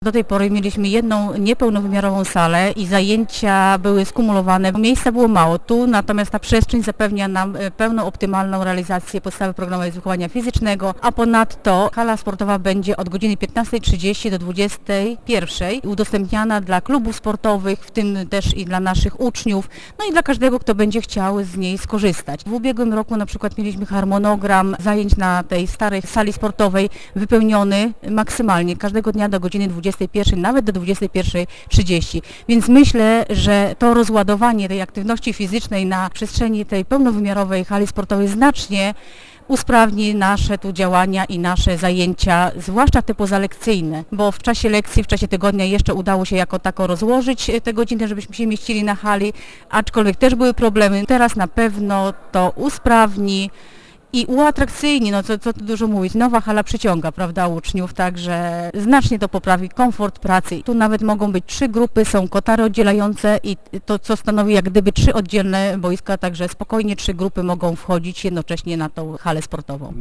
Uroczyste otwarcie nowego obiektu odbyło się we wtorek 1 września przy okazji inauguracji nowego roku szkolnego.